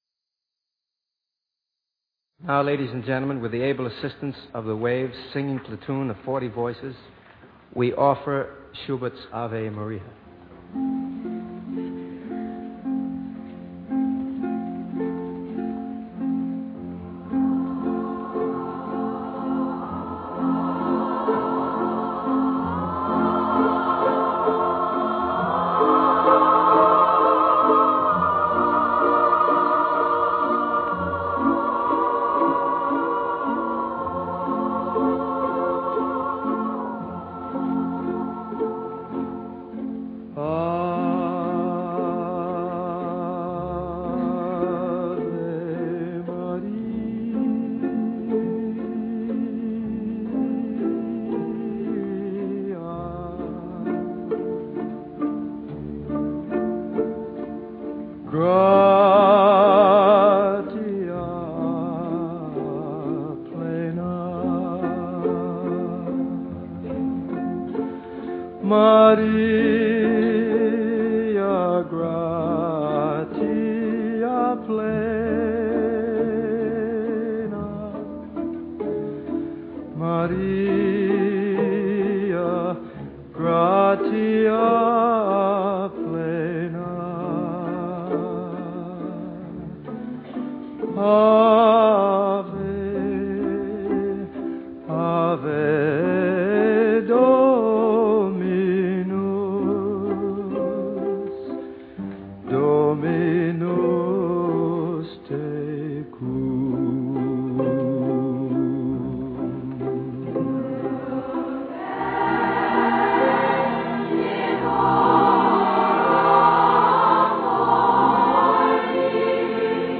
OTR Christmas Shows - Frank Sinatra and WAVES chorus - Ave Maria - 1945-03-28 CBS The Frank Sinatra Show (excerpt)